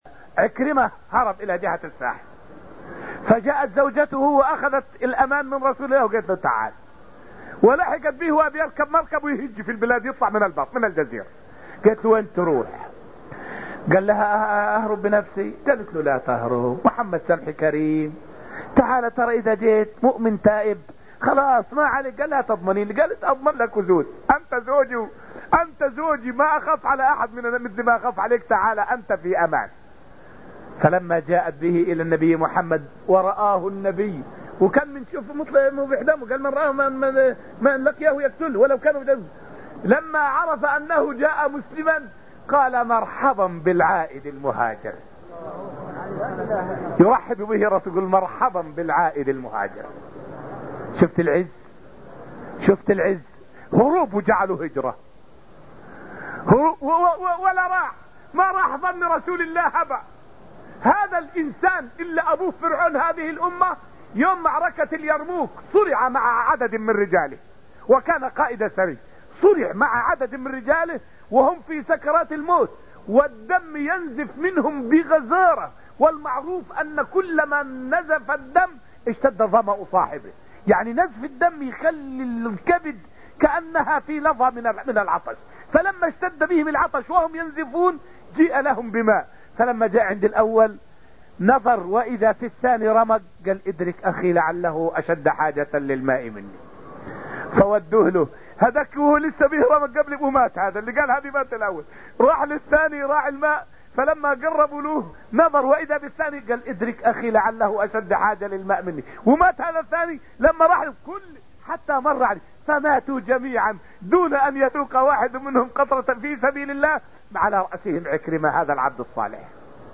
فائدة من الدرس السادس من دروس تفسير سورة القمر والتي ألقيت في المسجد النبوي الشريف حول قصة عكرمة بن أبي جهل يوم فتح مكة.